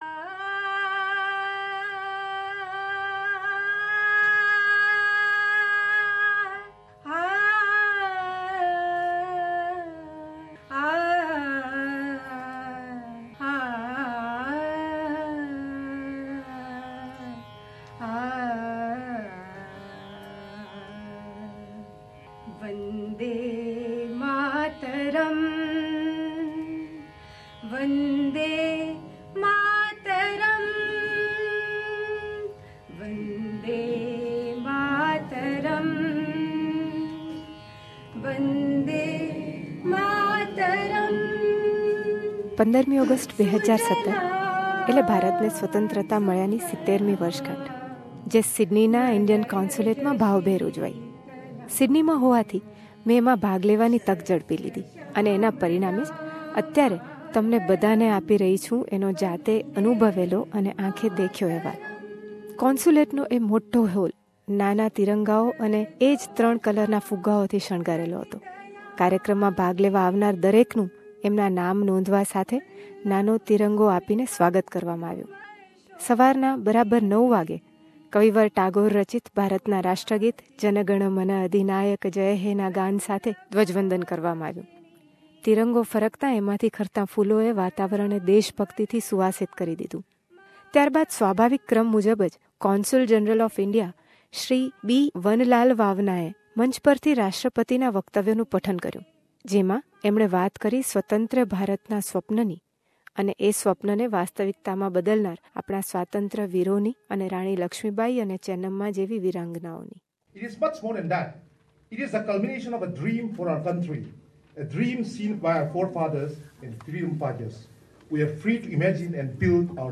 આંખે દેખ્યો અહેવાલ..